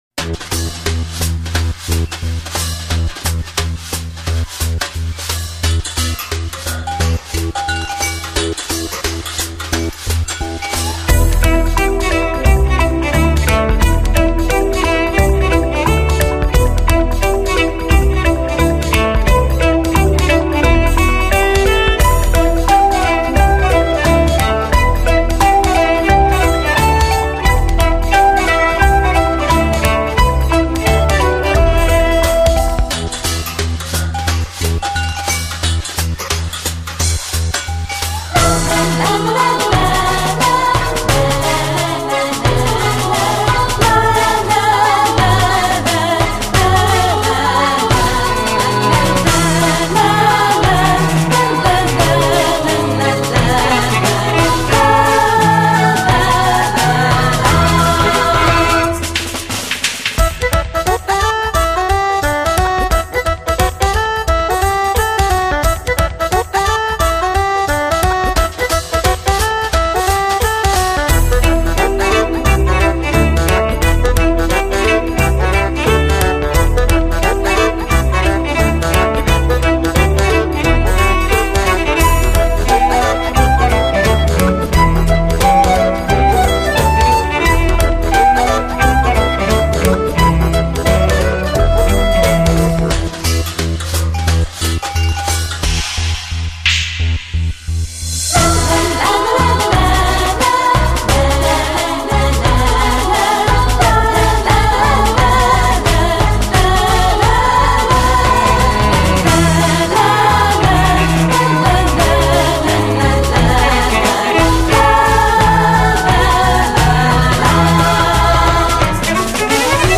远赴纽约录制完成的演奏专辑。